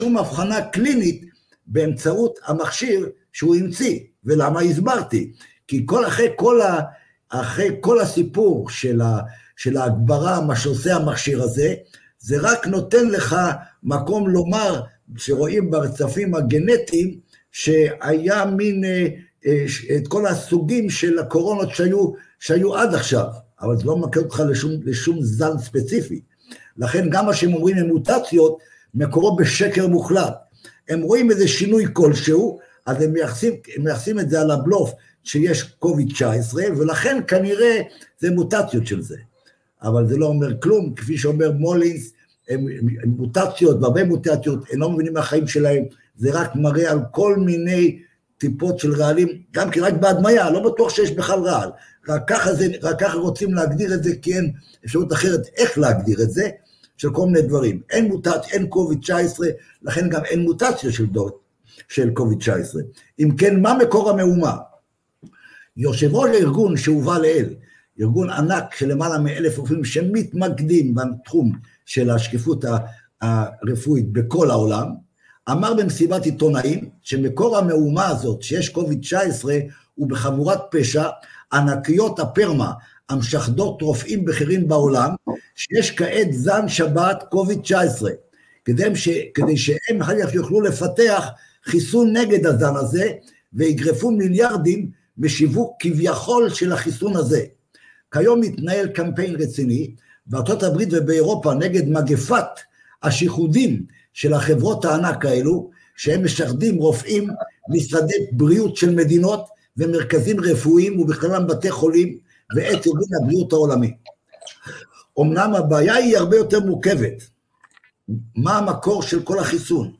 בסדרת הרצאות מרתקת